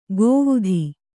♪ gōvudhi